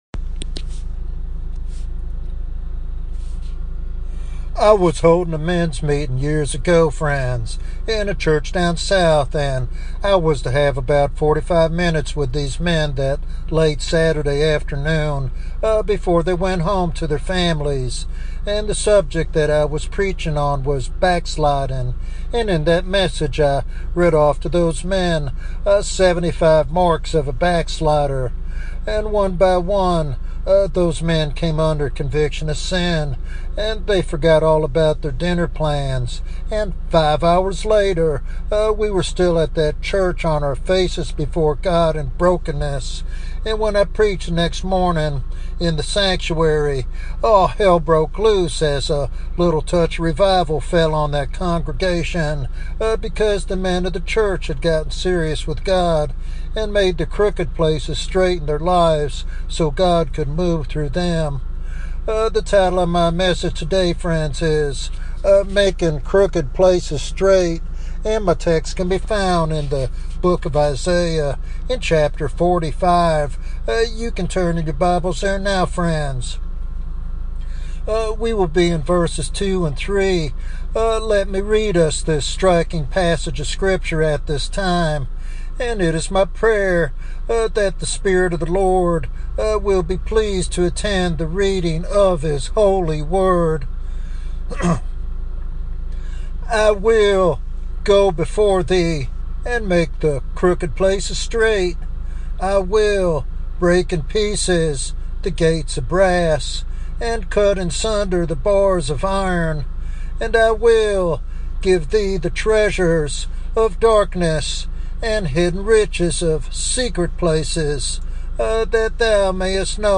This sermon challenges listeners to prepare themselves as clean vessels for God's transformative work in personal, church, and national revival.